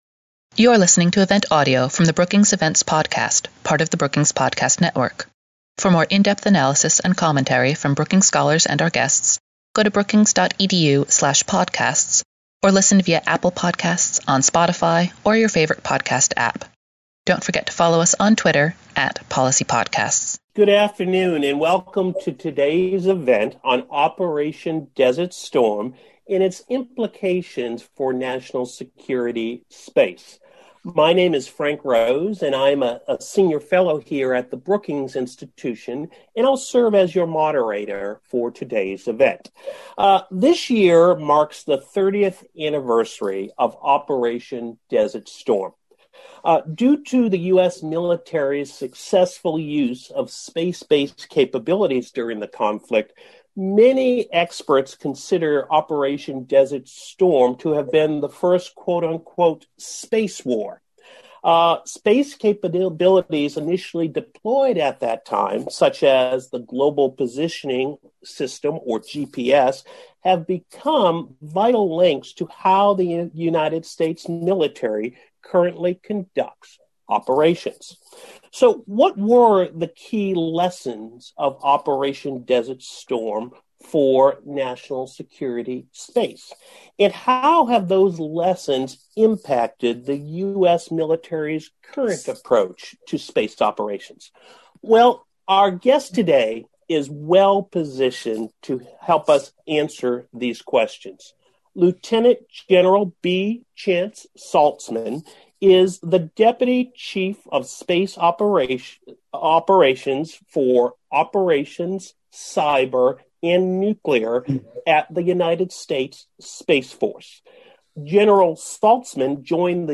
On Friday, March 19, the Foreign Policy program at Brookings will host Lt. Gen. B. Chance Saltzman, deputy chief of Space Operations with the United States Space Force, for a discussion on the role of space during the conflict and how technology has evolved over the last 30 years.